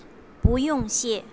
bu2 ke4 qi